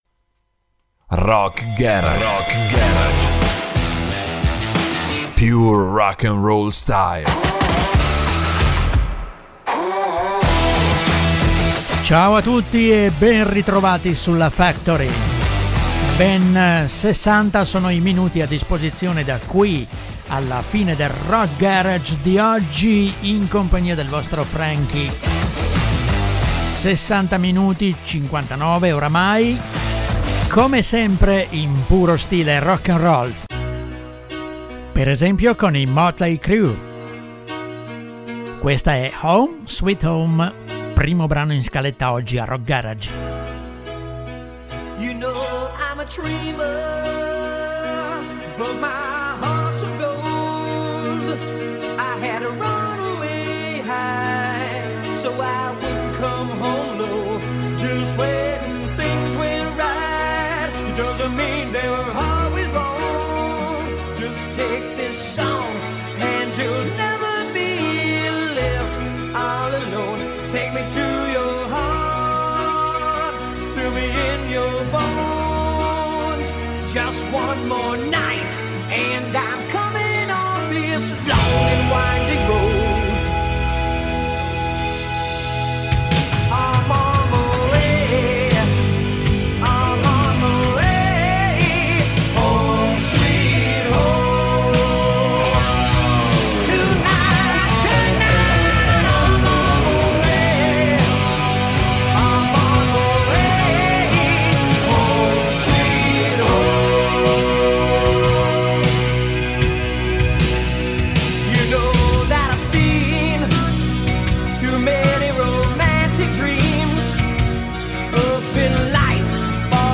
Le ultime novità e i classici del rock
rock-garage-stile-rocknroll.mp3